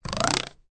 equationslide.ogg